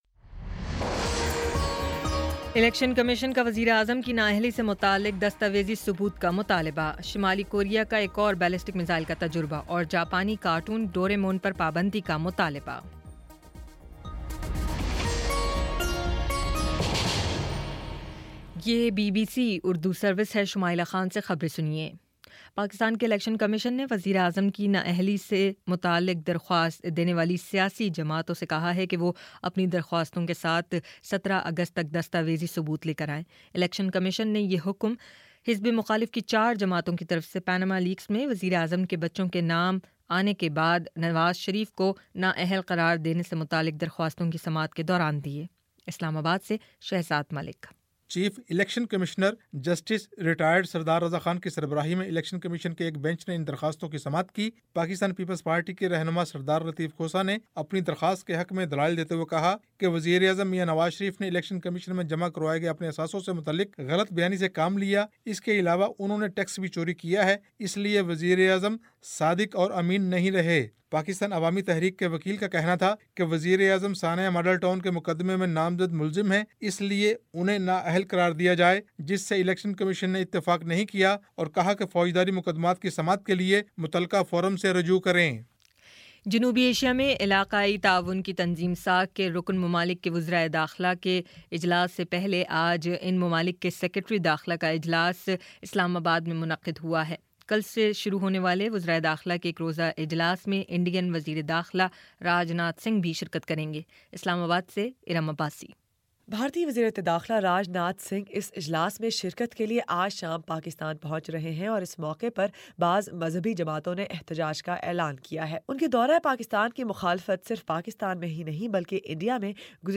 اگست 03 : شام چھ بجے کا نیوز بُلیٹن